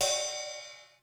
Index of /90_sSampleCDs/300 Drum Machines/Korg DSS-1/Drums01/06
Ride.wav